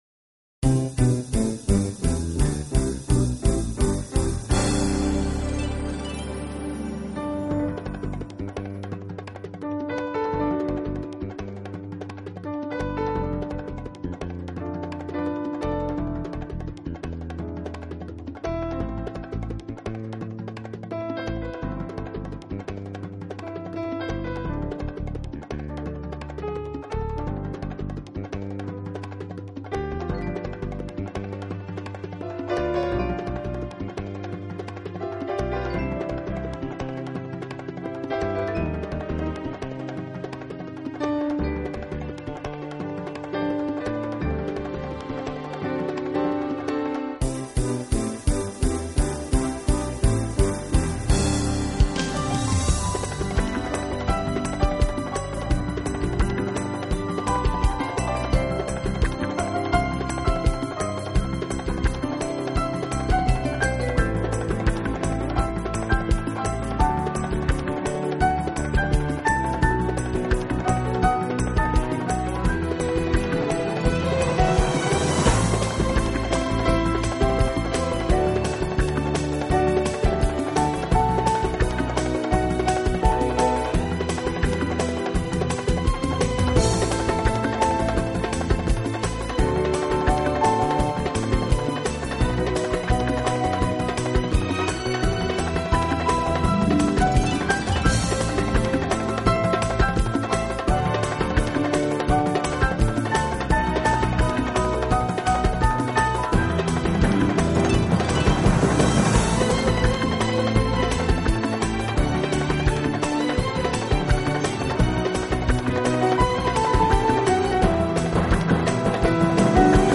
Genre: Easy Listening